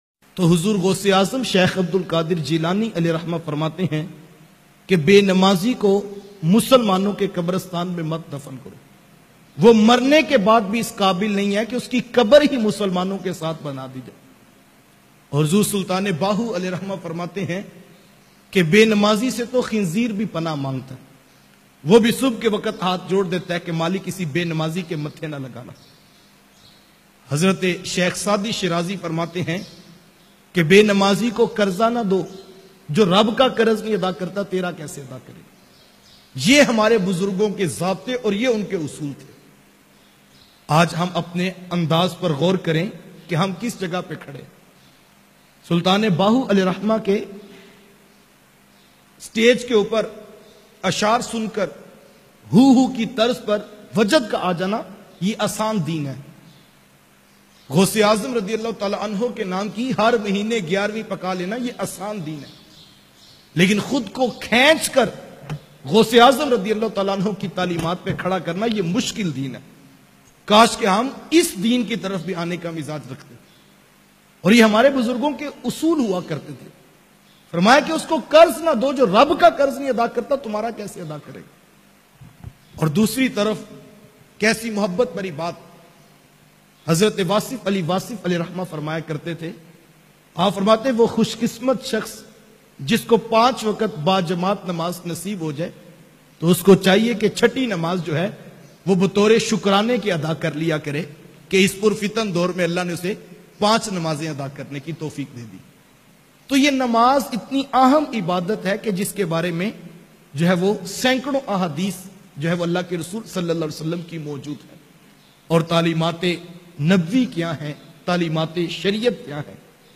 Ek Be Namazi ki saza bayan mp3